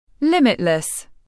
Limitless /ˈlɪm.ɪt.ləs/